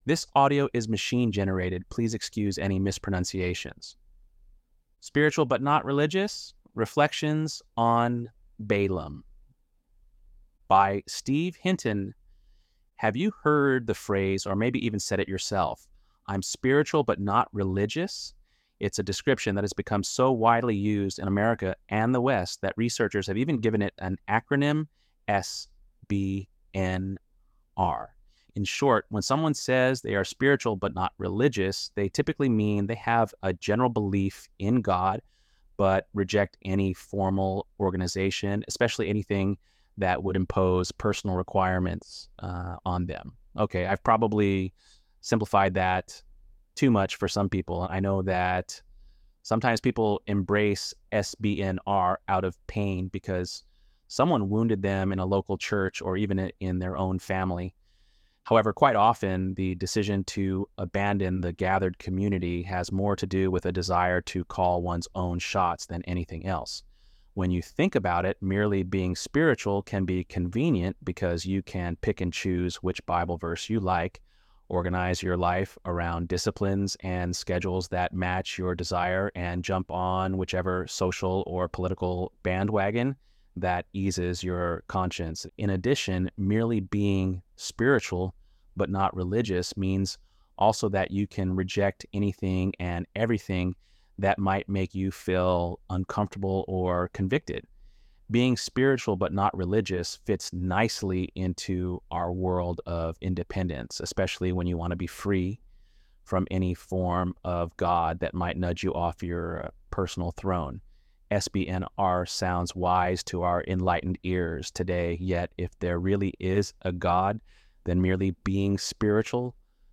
ElevenLabs_9.11.mp3